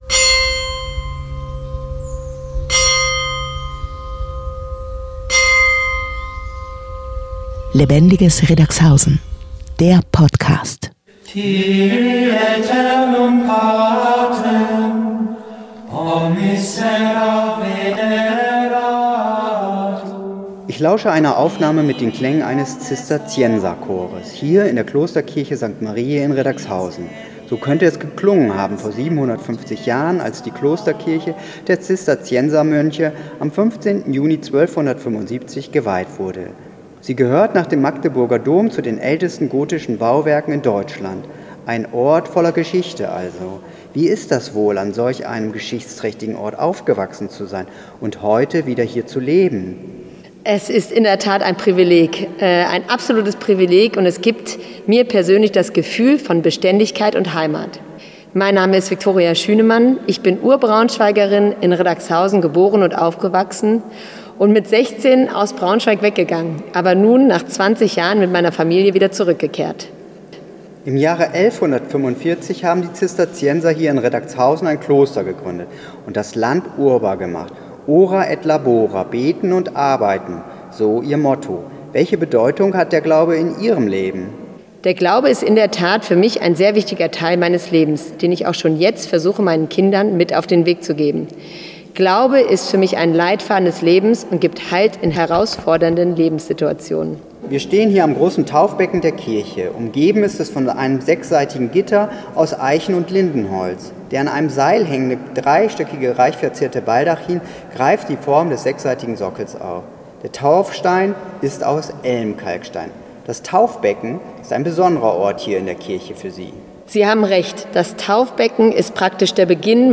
Die Interviews erscheinen ab heute in regelmäßigen Abständen.